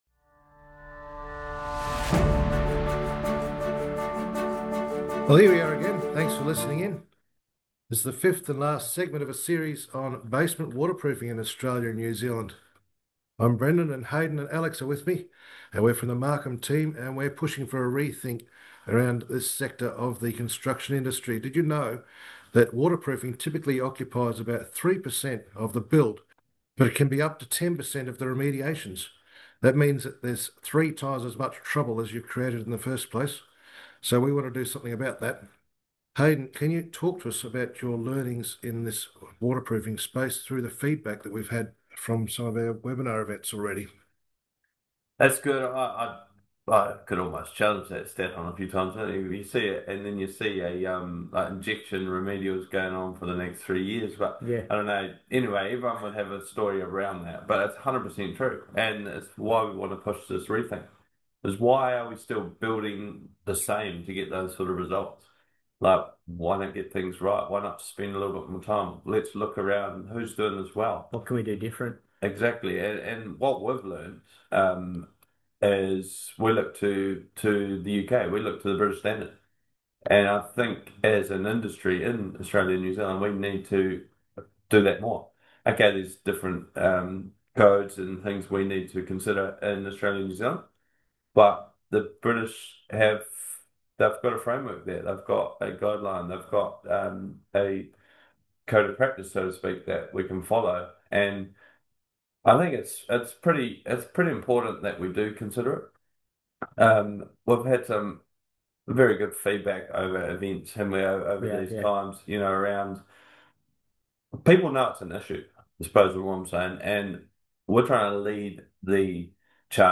In this series of interviews, the MARKHAM team will walk through the science and challenges of concrete durability, what goes wrong in the field and the advanced methods available to restore and enhance service life to concrete construction.